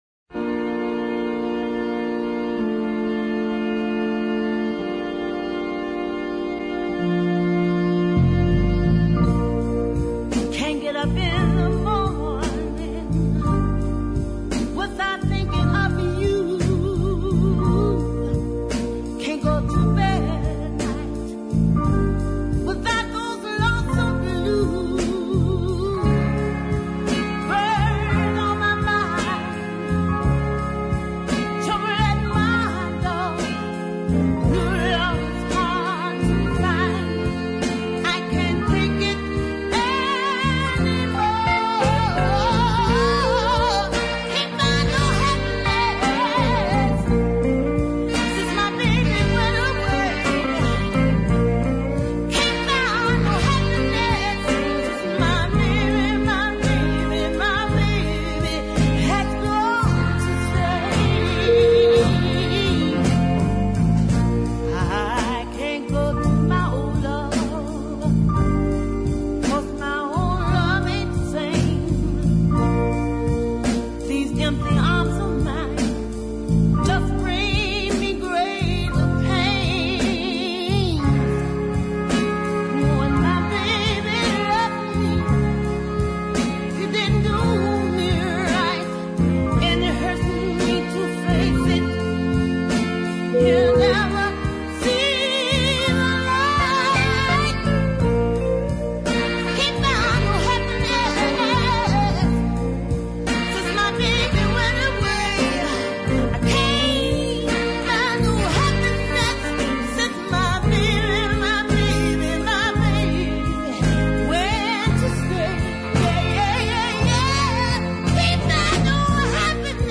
Both sides of this disc are fantastic deep soul.
her tortured screams